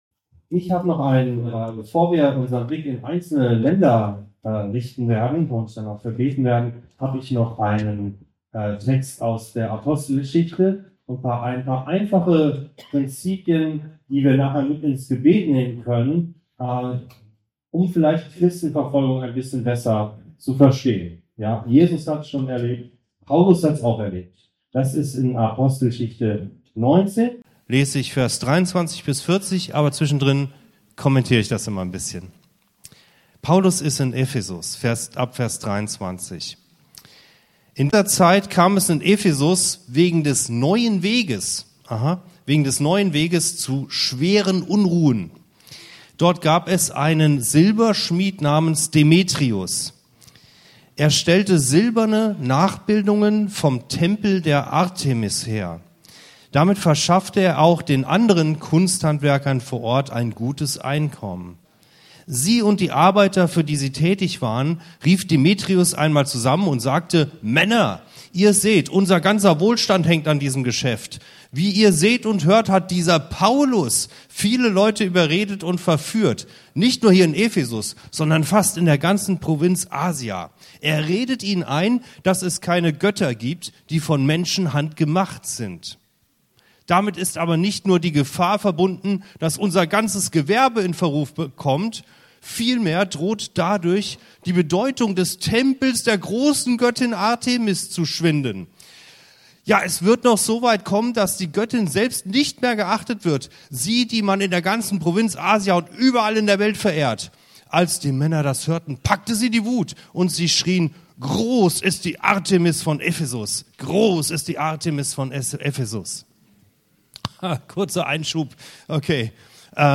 Warum werden Christen verfolgt? Lektionen aus der Apostelgeschichte ~ Anskar-Kirche Hamburg- Predigten Podcast